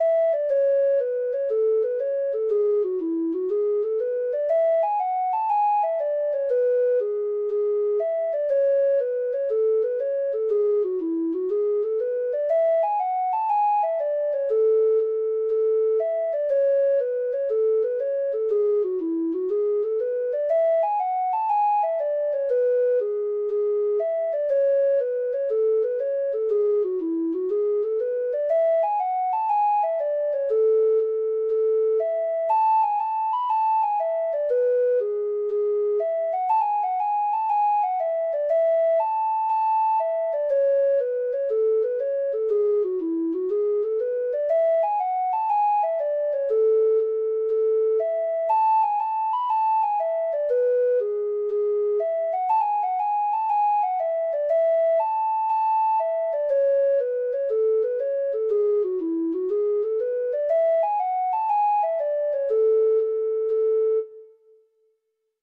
Ireland    hornpipe